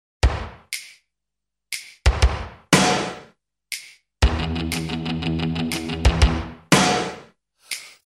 • Качество: 128, Stereo
без слов
ударные
classic rock